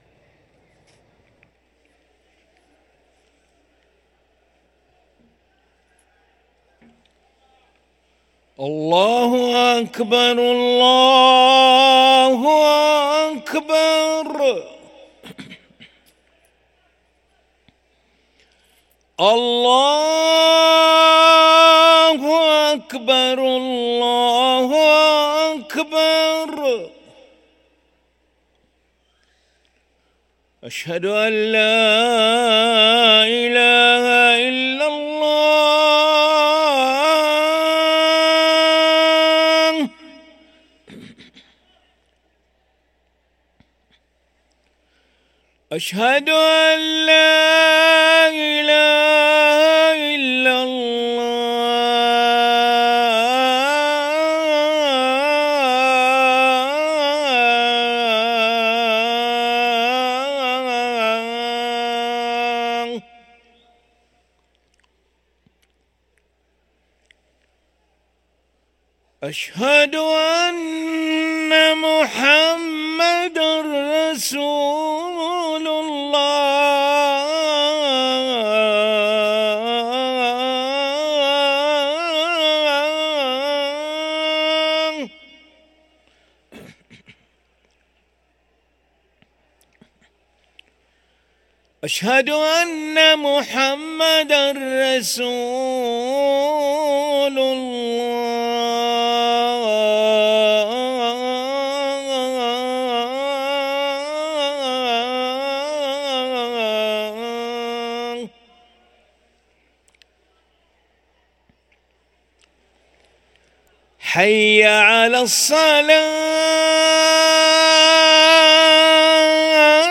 أذان العشاء للمؤذن علي ملا الخميس 9 جمادى الأولى 1445هـ > ١٤٤٥ 🕋 > ركن الأذان 🕋 > المزيد - تلاوات الحرمين